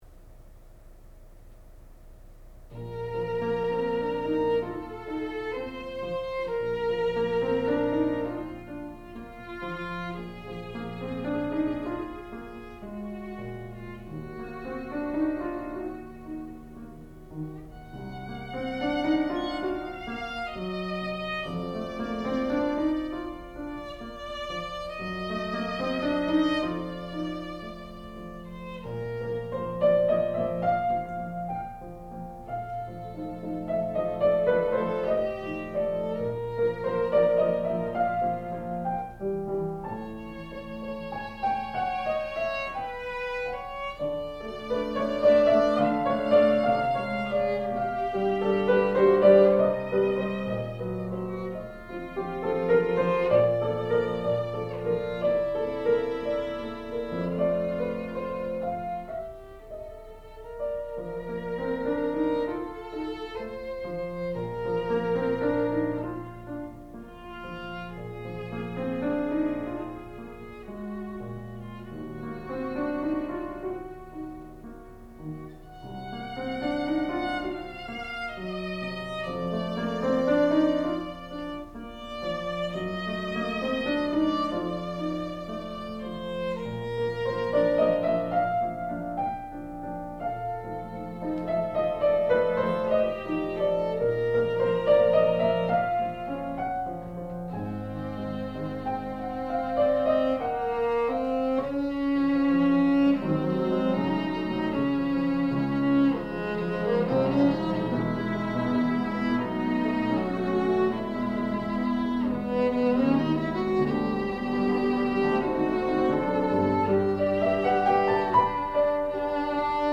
sound recording-musical
classical music
violin
piano
Graduate recital